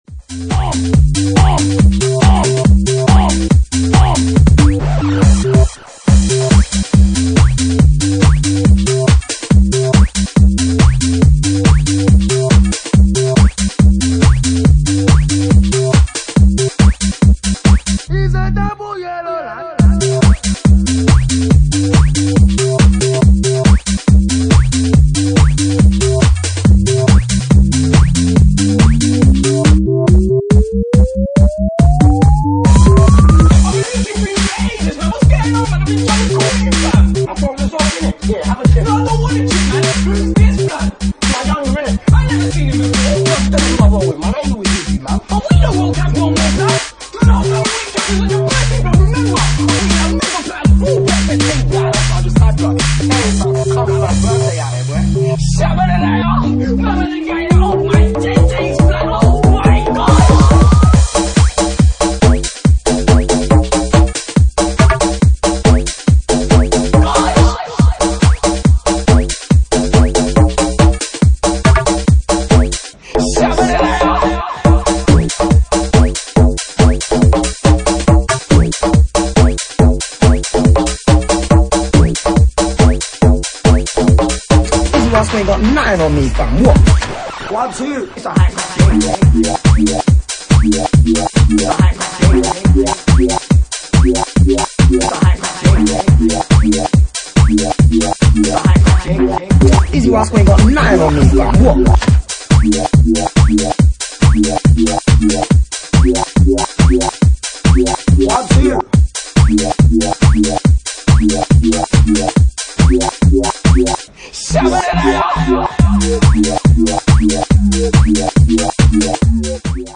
Genre:Bassline House
Bassline House at 141 bpm
dub mix
expect warps donks and filthy drops!